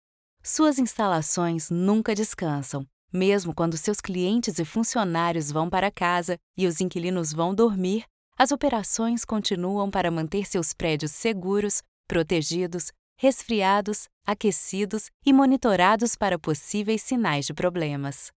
Portuguese (Brazil)
Commercial, Young, Soft
Corporate